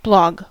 Ääntäminen
US : IPA : /ˈblɑɡ/